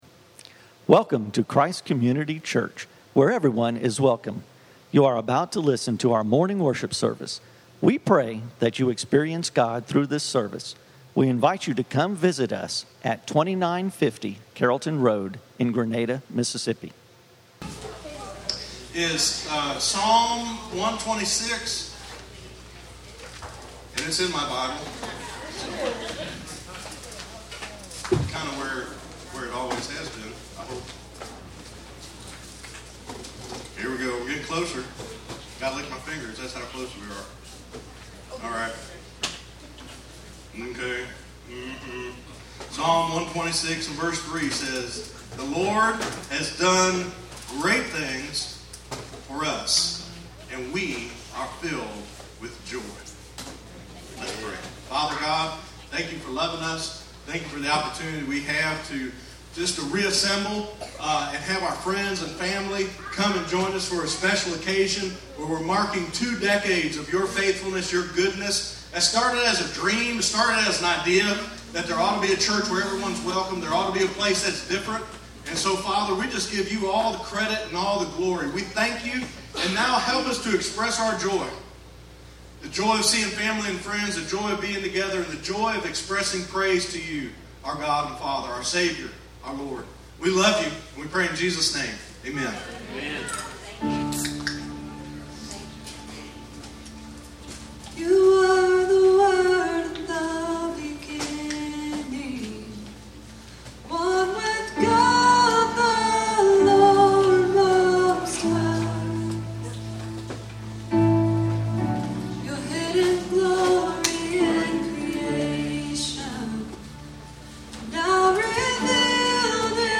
20th Anniversary Worship Celebration held in the evening on 09/30/2018